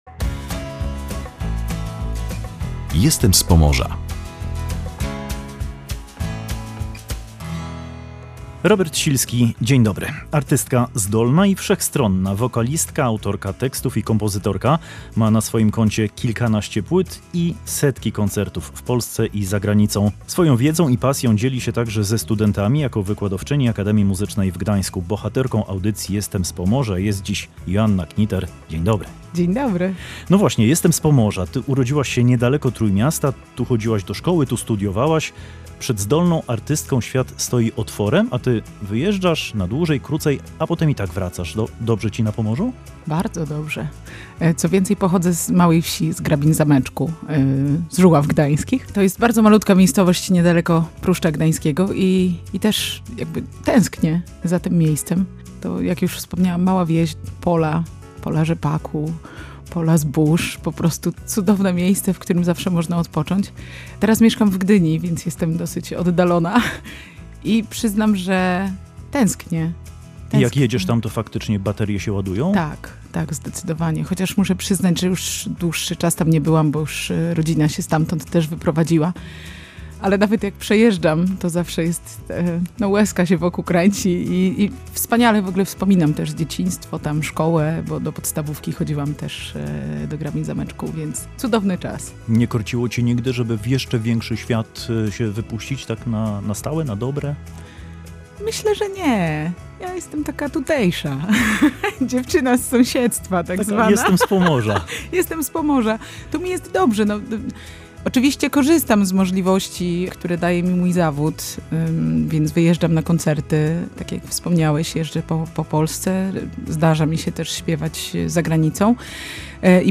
Na scenie jest wulkanem energii, w radiowym studiu także.